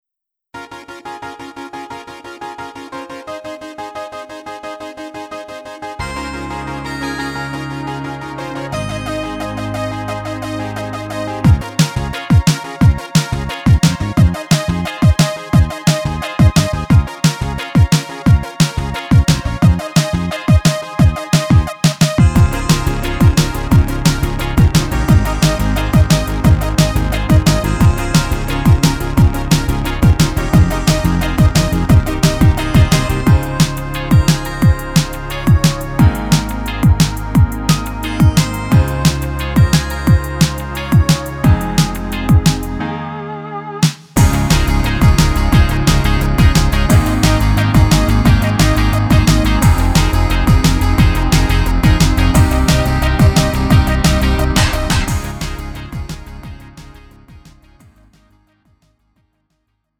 음정 원키 3:03
장르 가요 구분 Lite MR